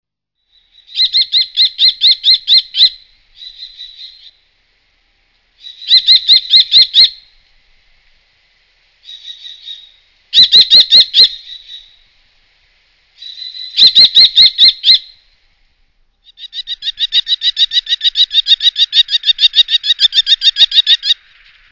gheppio c.wav